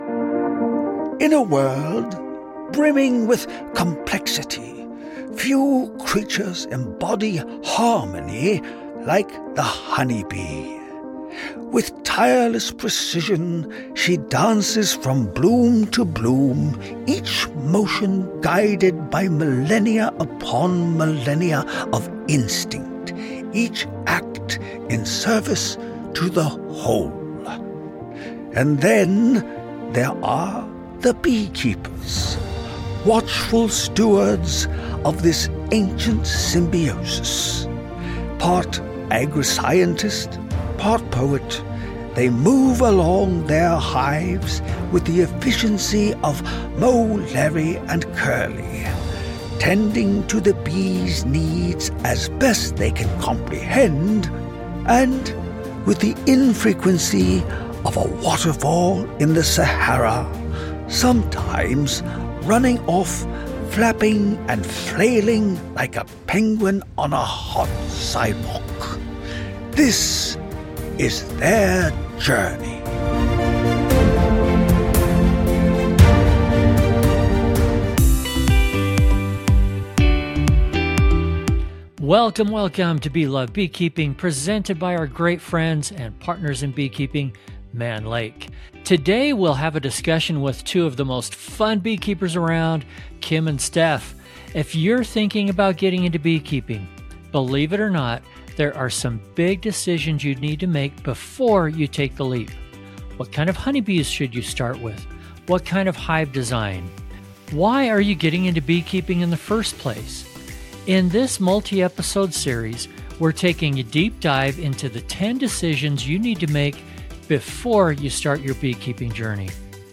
making this one of the most comprehensive beginner-focused beekeeping conversations you'll find anywhere.